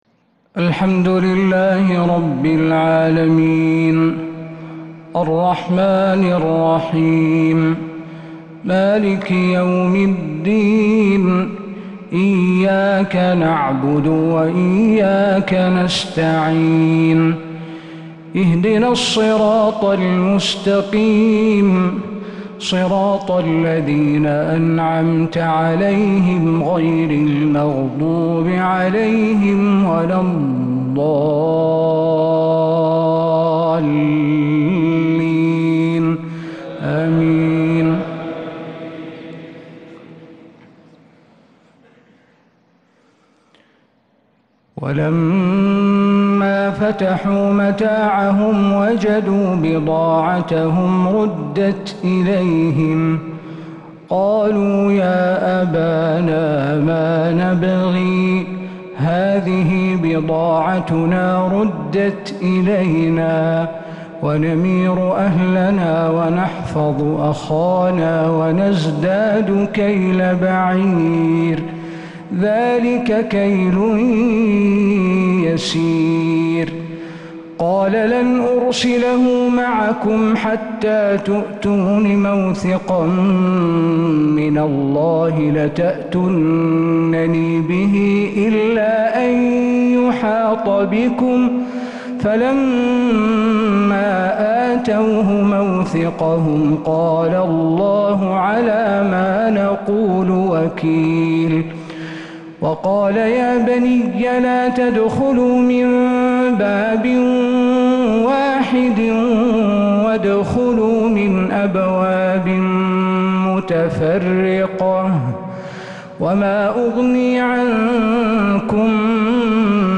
فجر الأربعاء 12 صفر 1447هـ | من سورة يوسف 65-87 | Fajr prayer from surat Yusuf 6-8-2025 > 1447 🕌 > الفروض - تلاوات الحرمين